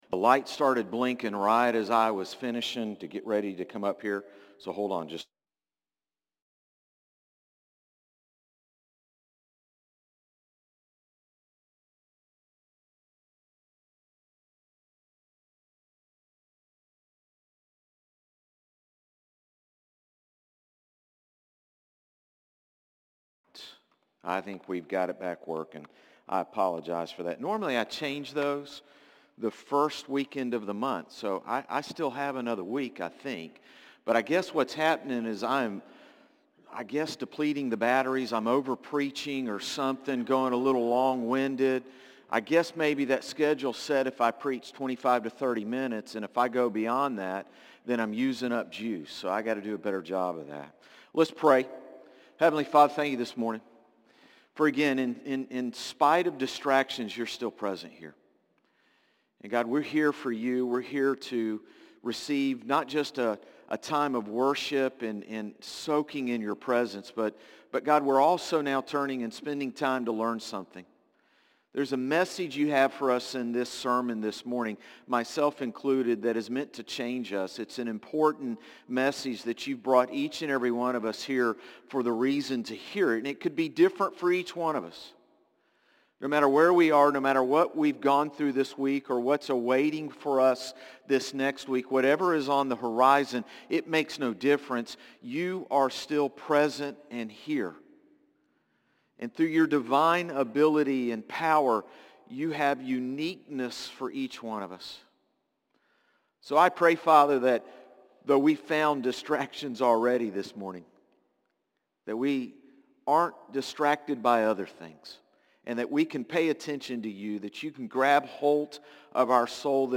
Sermons - Concord Baptist Church